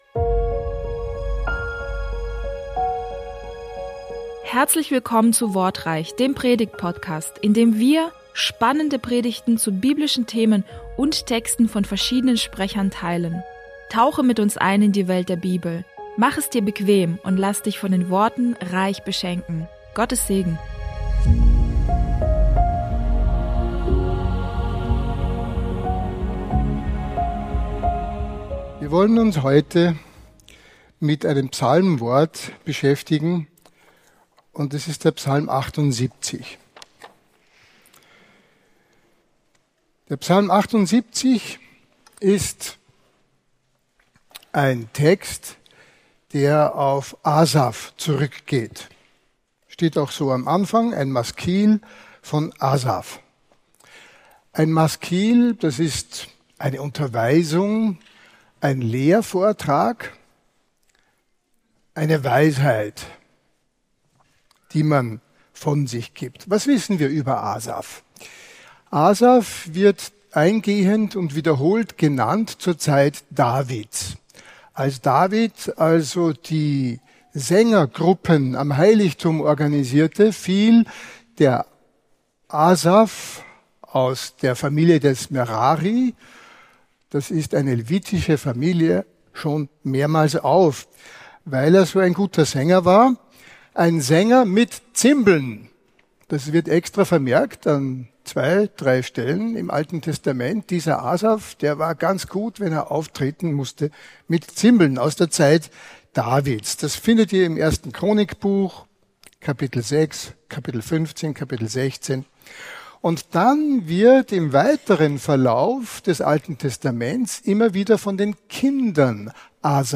Hier hörst du Predigten aus Bogenhofen von unterschiedlichen Predigern, die dich näher zu Gott bringen und deinen Glauben festigen.